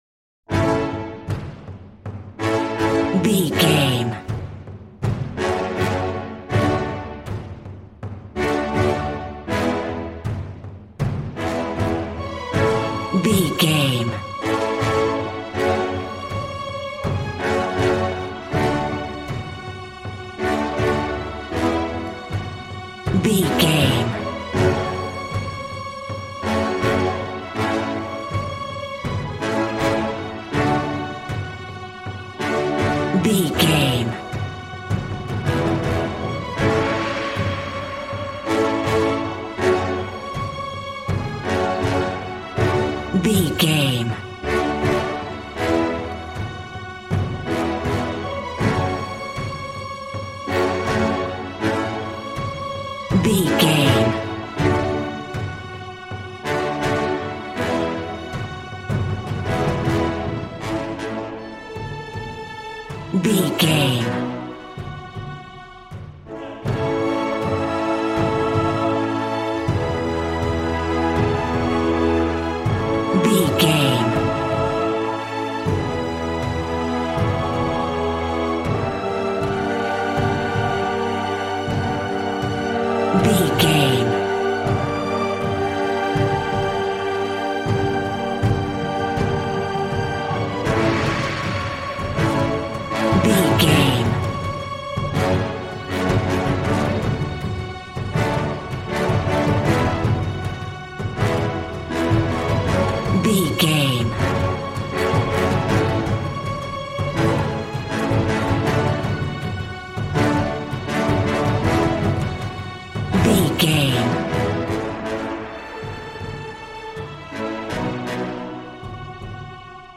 Regal and romantic, a classy piece of classical music.
Aeolian/Minor
G♭
regal
cello
double bass